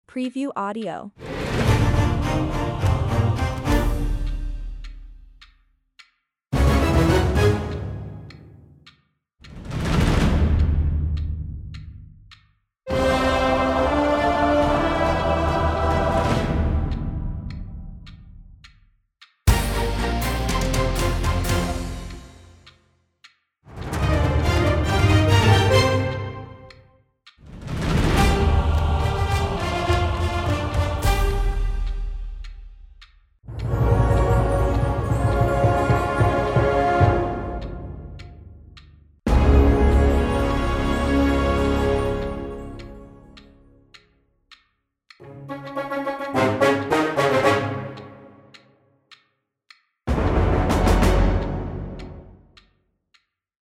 Action-Adventure_Musical_SFX_V1.mp3